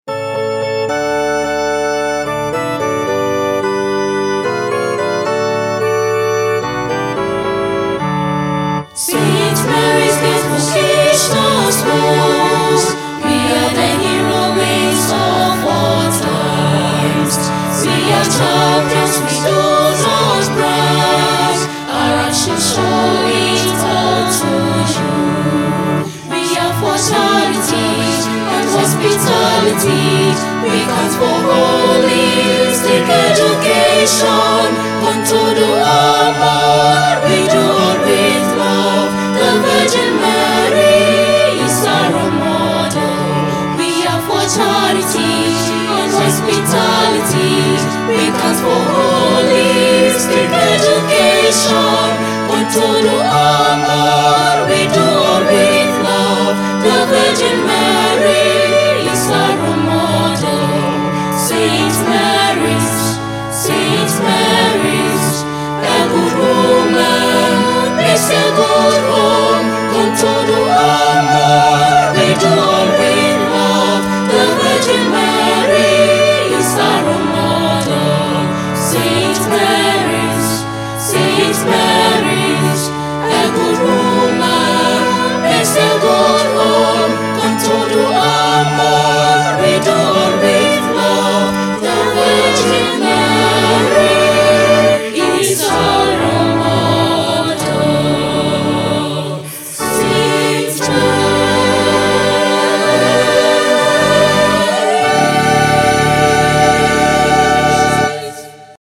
Himno del acontecimiento / Hymn of the event